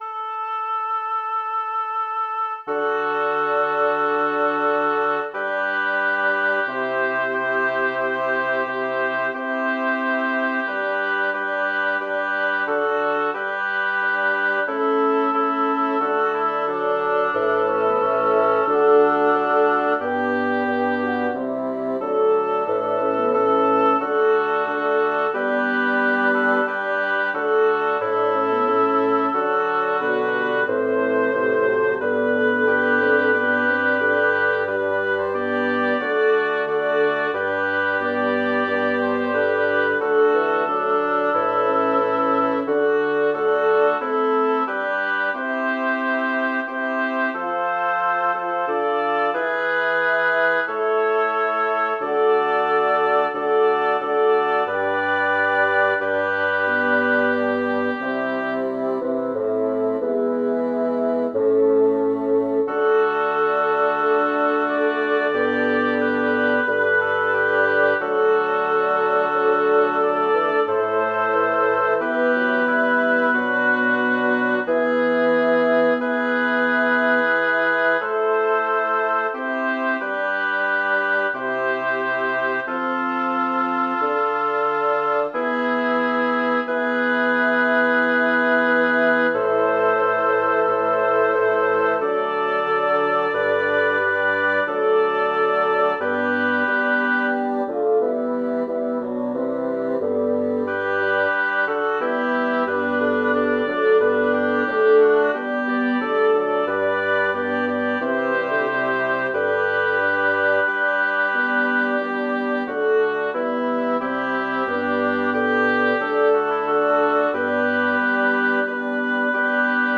Title: Si quis diligit me Composer: Vincenzo Ruffo Lyricist: Number of voices: 6vv Voicing: SSATTB Genre: Sacred, Motet
Language: Latin Instruments: A cappella